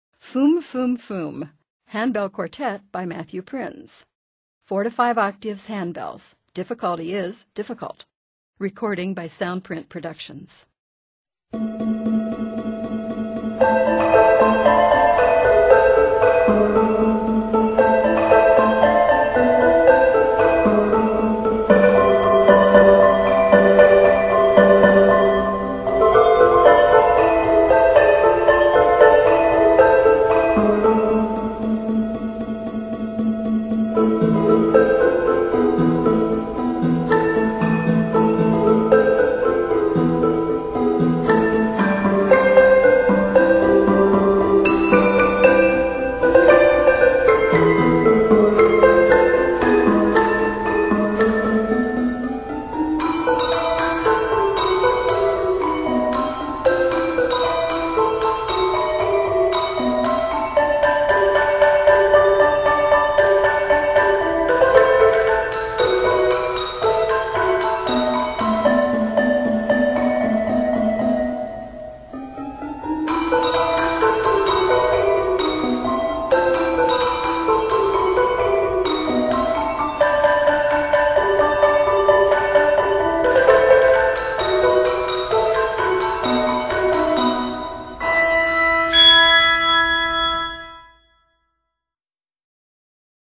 Arranged in a minor and e minor, measures total 61.